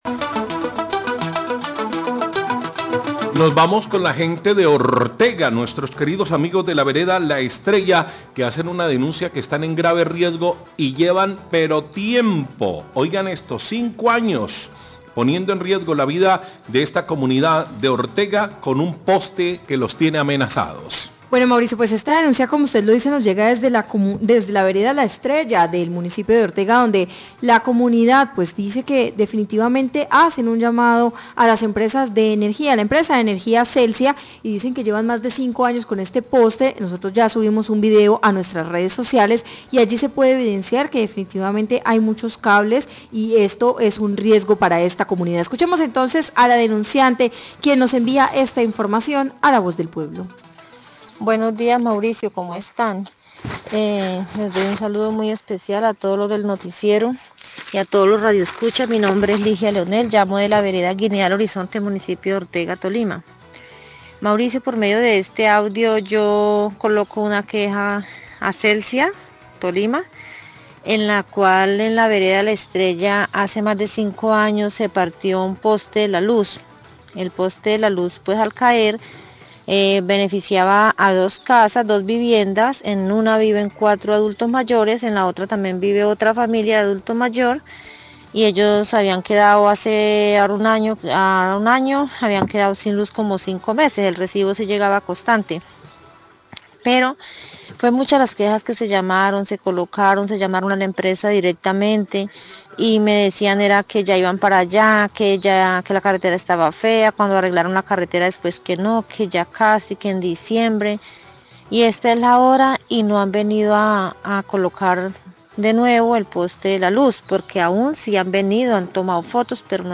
Radio
queja ciudadana
La oyente dice que son tres años llamando y a pesar de los llamados Celsia no se ha reportado